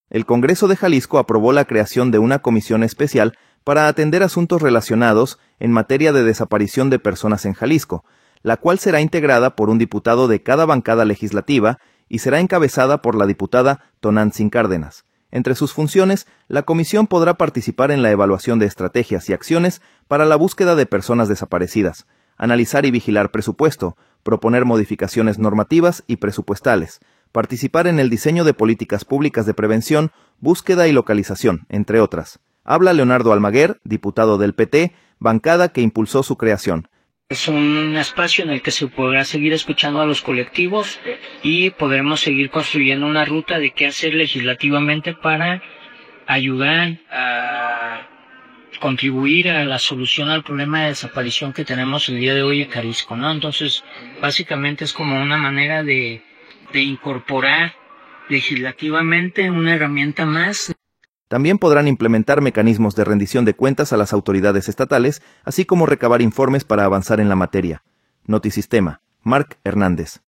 Habla Leonardo Almaguer, diputado del PT, bancada que impulsó su creación.